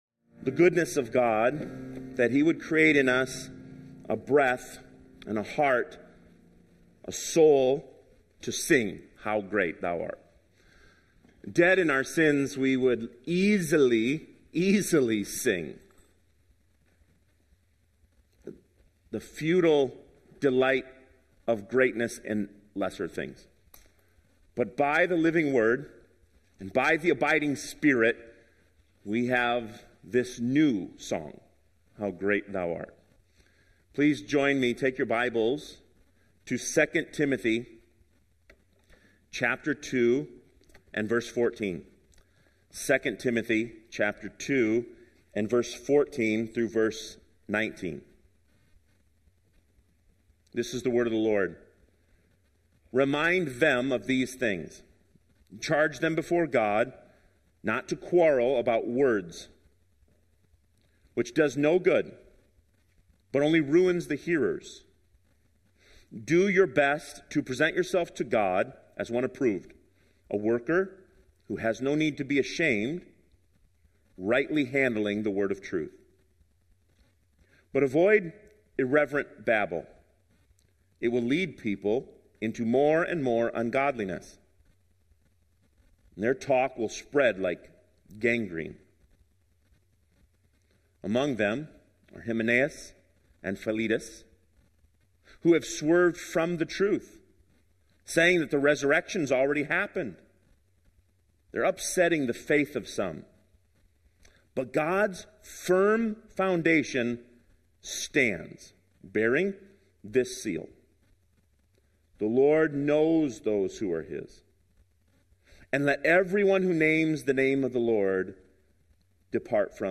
Sermons Archive - Page 4 of 185 - Immanuel Baptist Church - Wausau, WI